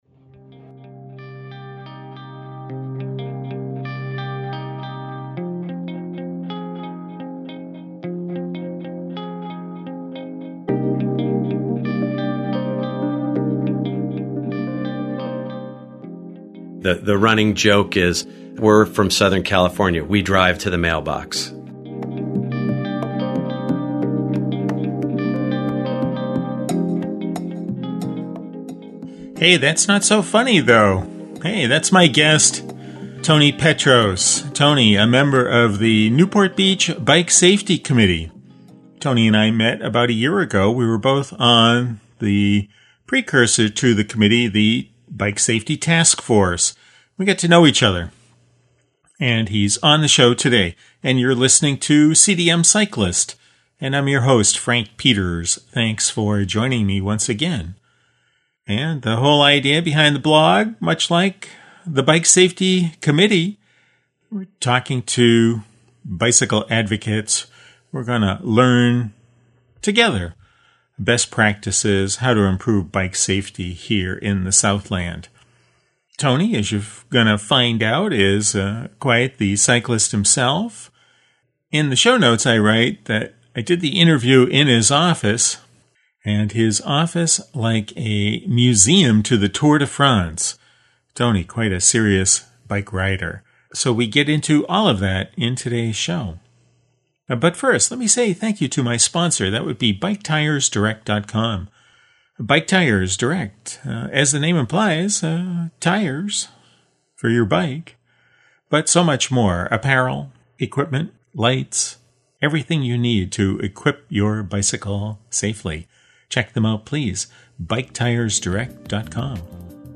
For the interview we’re in his office; it’s like a museum, the walls are covered in photos and vintage Tour de France posters.